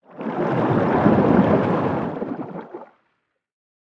Sfx_creature_arcticray_swim_twist_02.ogg